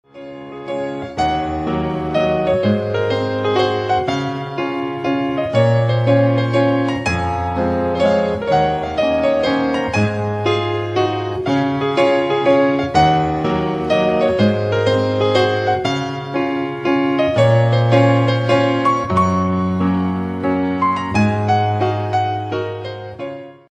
铃声版